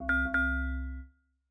steeltonguedrum_c1c1.ogg